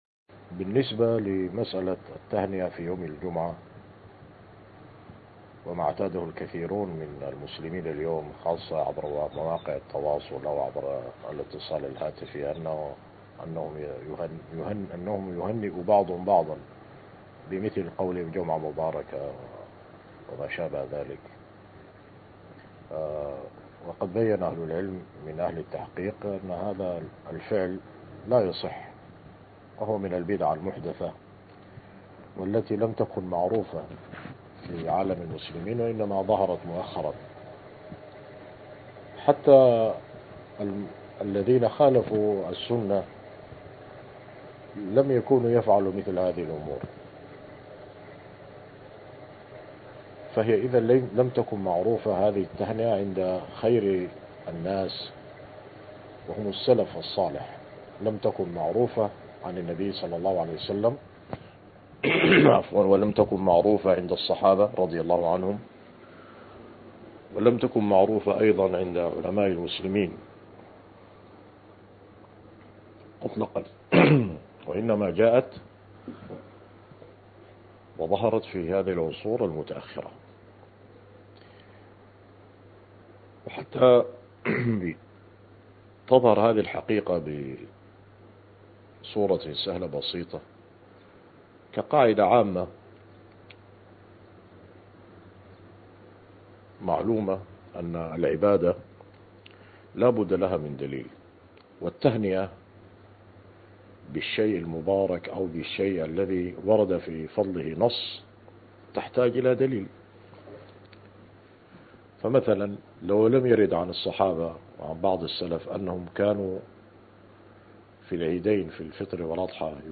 [إجابة صوتية]